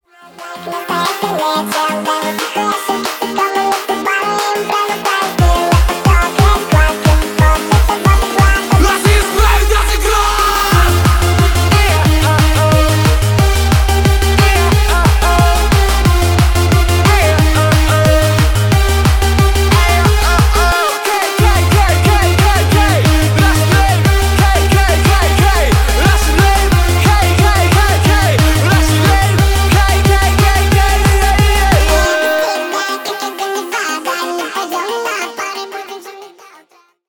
Поп Музыка
ритмичные
весёлые